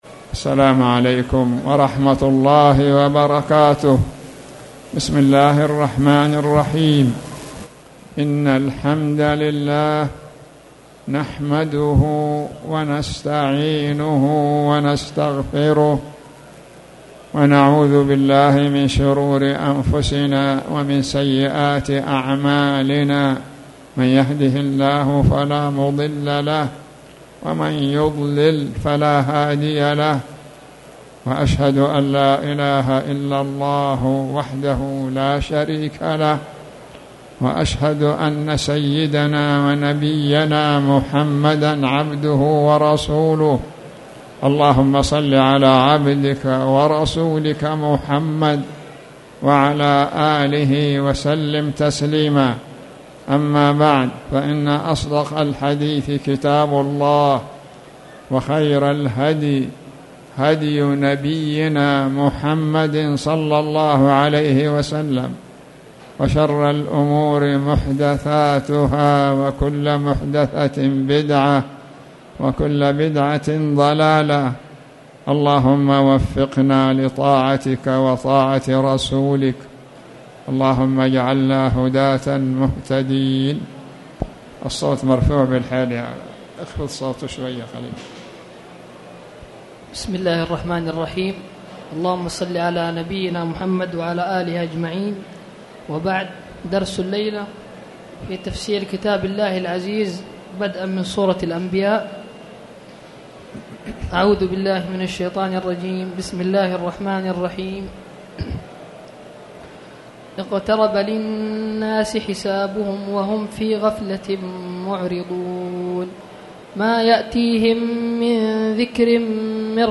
تاريخ النشر ١١ شوال ١٤٣٨ هـ المكان: المسجد الحرام الشيخ